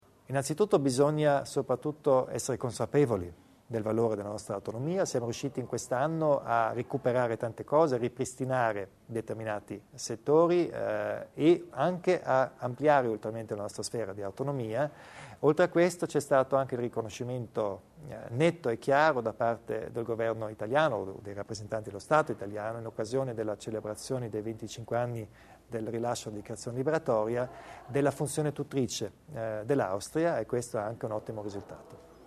Il Presidente Kompatscher spiega il ruolo dell'autonomia in ambito europeo
Più Europa per dare risposte adeguate alle sfide moderne, autonomia come strada maestra per l'autogoverno del territorio. Incontro di fine anno con Kompatscher.